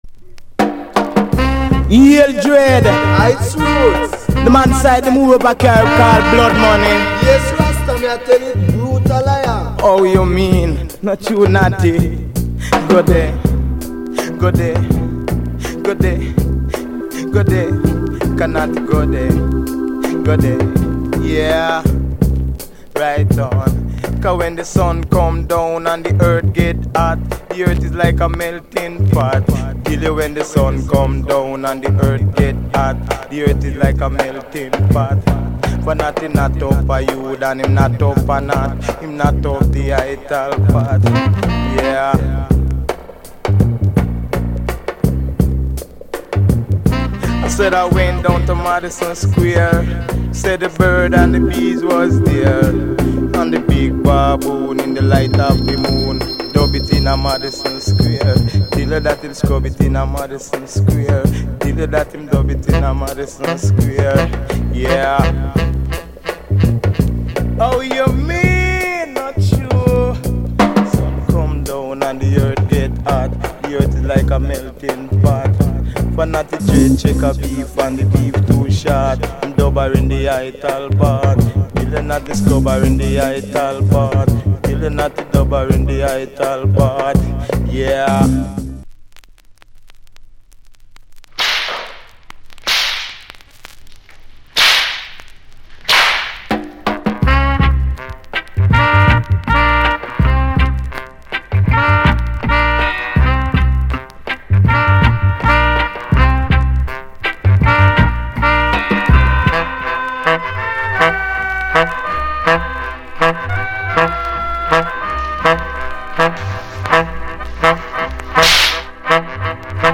Old Skool Dee-Jay！